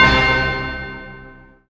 SI2 METAL03L.wav